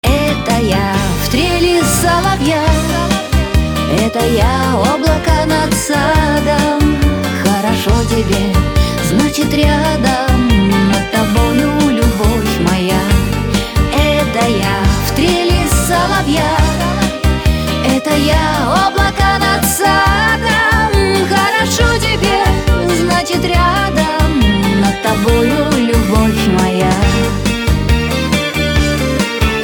поп
женский вокал
dance
спокойные
vocal